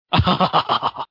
laugh